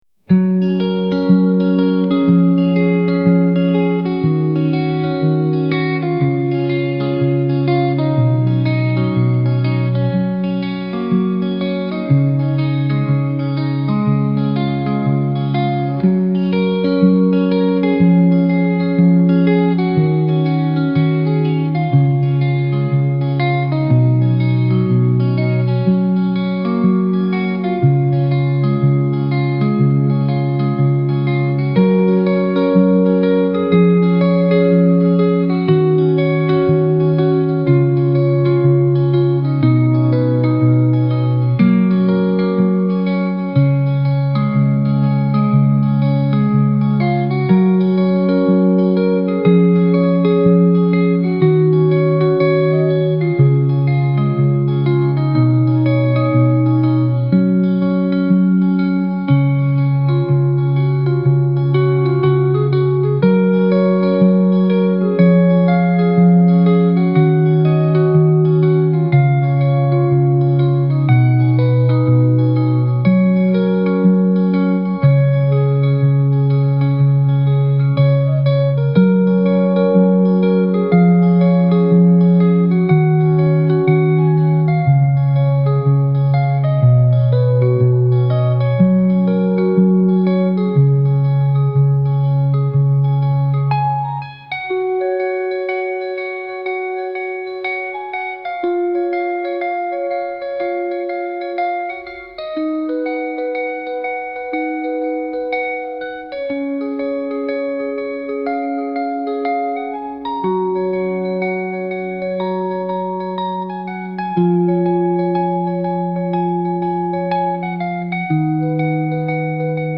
雨音入り癒しのlo-fi/cillミュージック